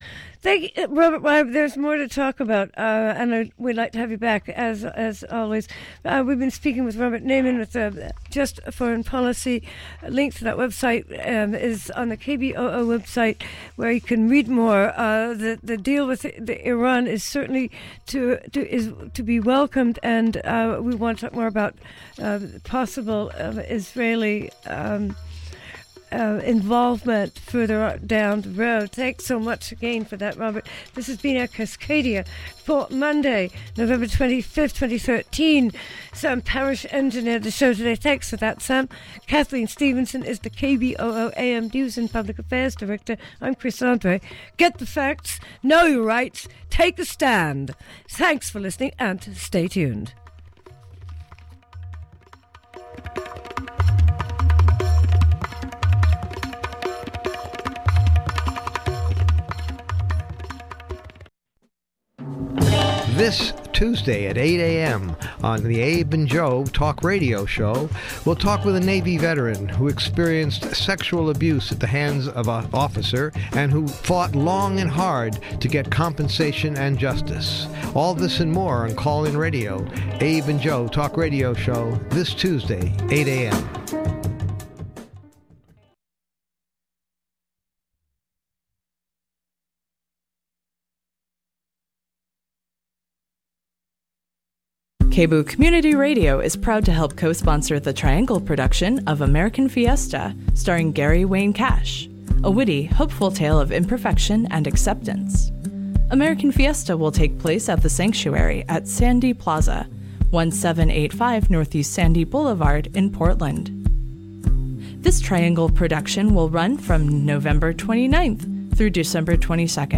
THE FUTURE IS NOT ALL DOOMED - A Conversation with Carl Safina